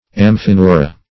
amphineura - definition of amphineura - synonyms, pronunciation, spelling from Free Dictionary
Amphineura \Am`phi*neu"ra\, n. pl. [NL., fr. 'amfi` + ? sinew,